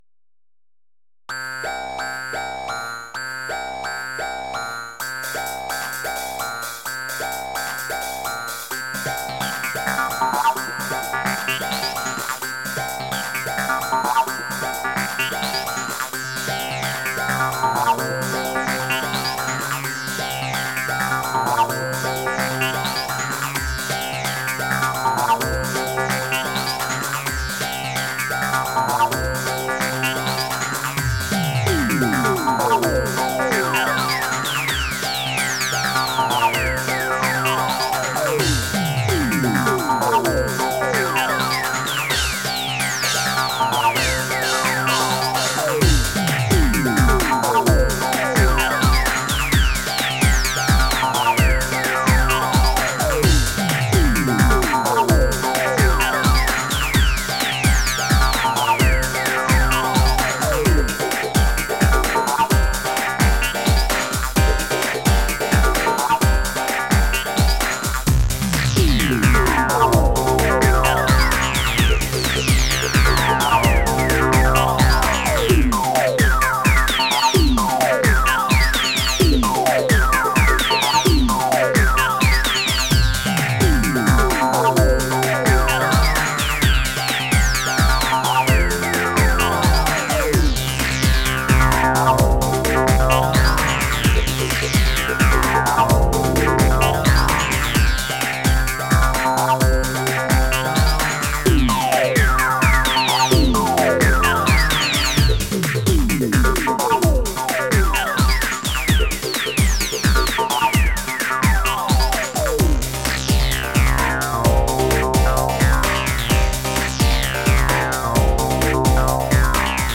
Mede in MTV Music Generator 2, in 2025
Music / Trance